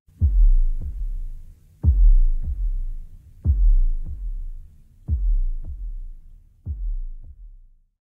pulse.ogg